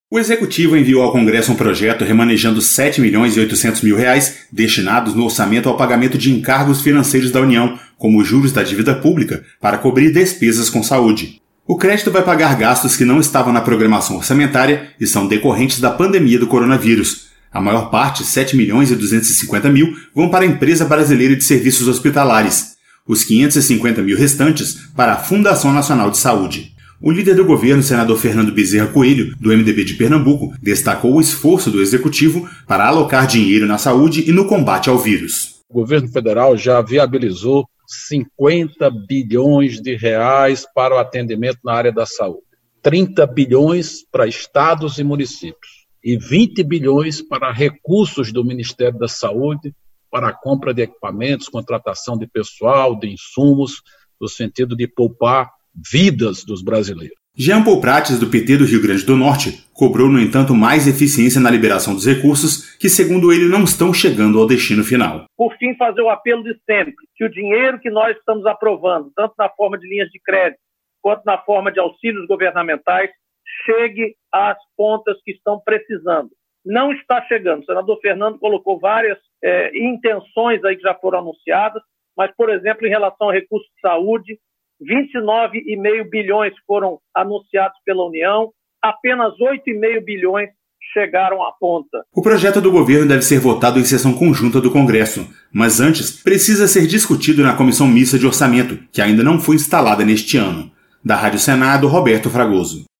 Senador Fernando Bezerra Coelho
Senador Jean Paul Prates